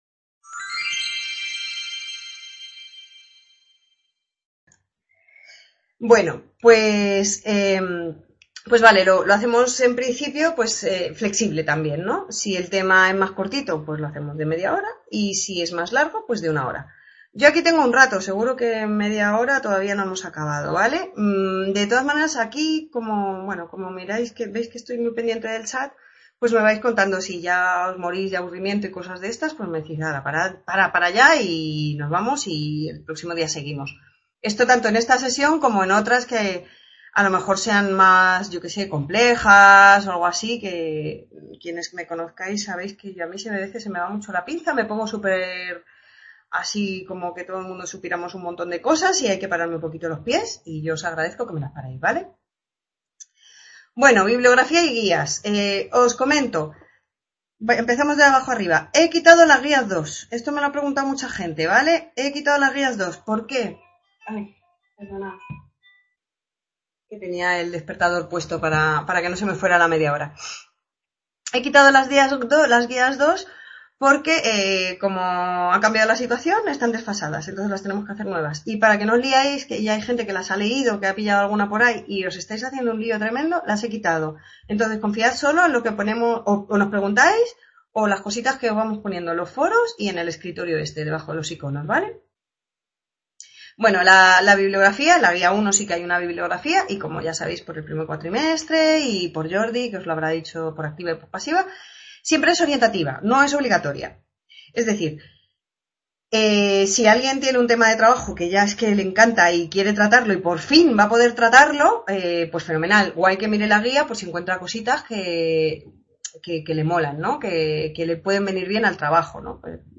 primera clase